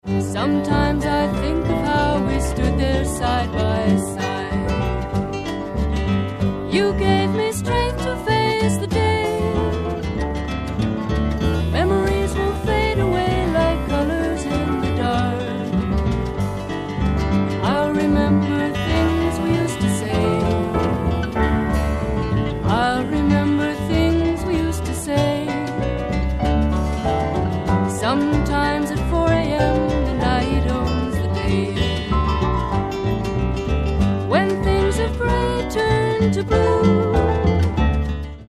ACID FOLK/RARE GROOVE